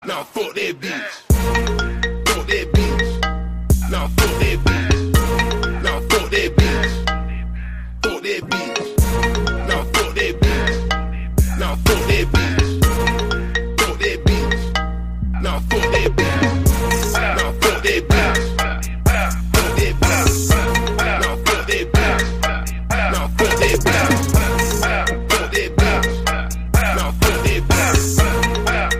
Рингтоны » на смс